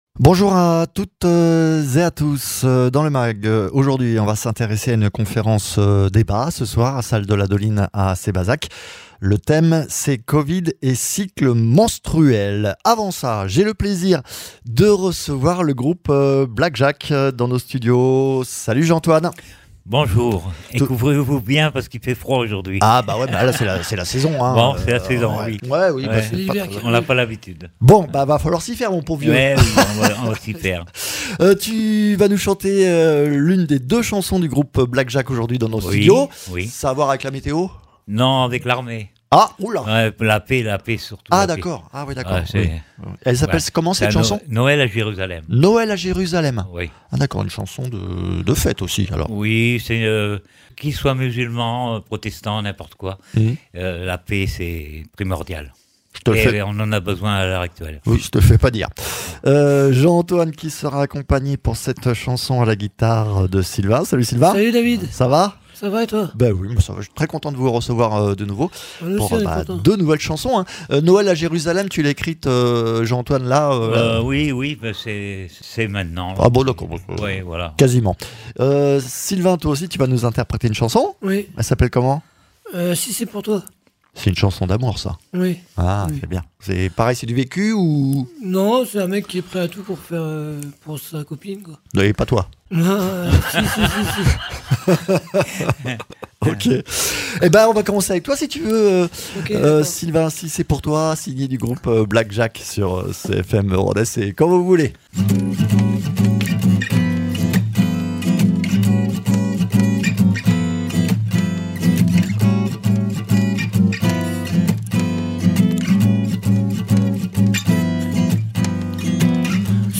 Deux nouvelles chansons en live depuis nos studios signés du groupe Black Jacques et puis une conférence débat ce soir à Sébazac sur le thème COVID et cycles menstruels c’est le menu du mag